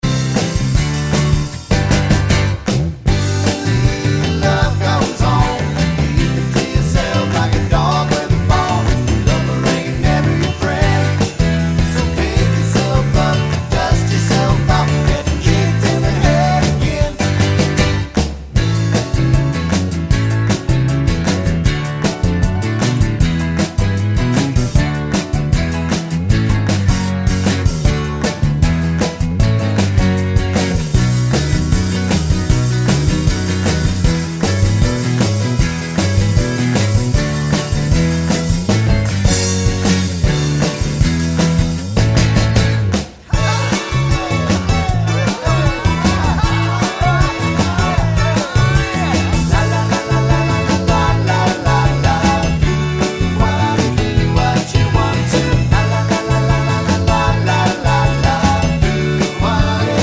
no Backing Vocals Rock 2:26 Buy £1.50